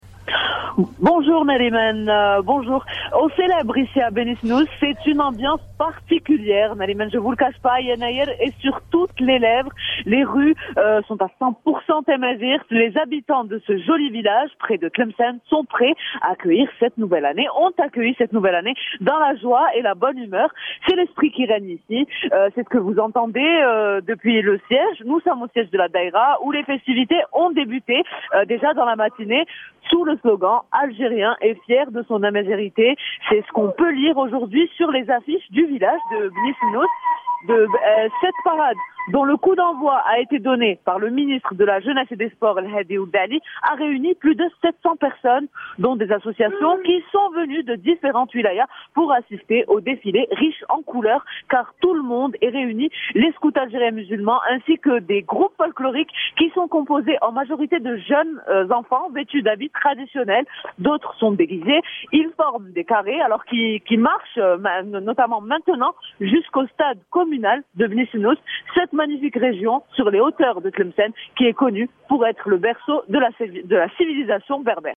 Ambiance festive représentant toutes les régions du pays.